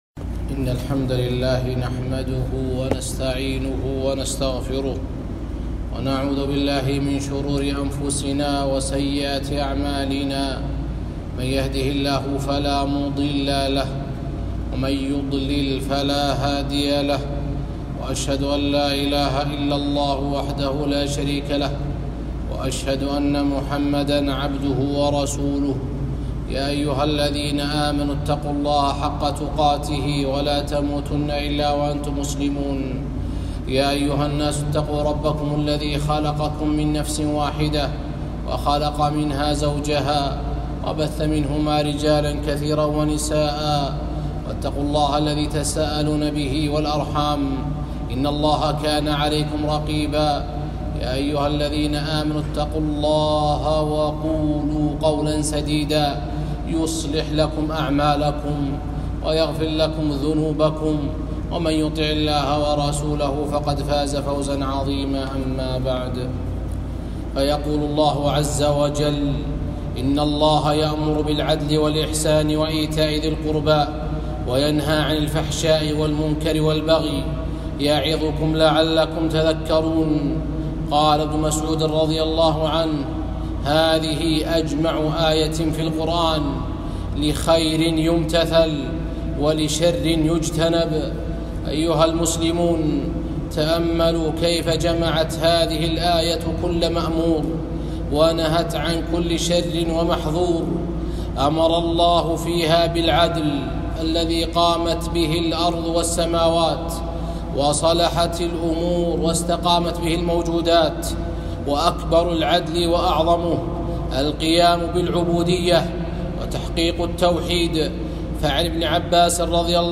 خطبة - أَجْمَعُ آيَةٍ فِي الْقُرْآنِ لِلْخَيْرِ وَالشَّرِّ